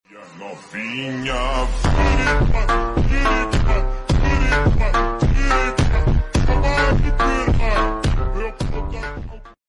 brazilian music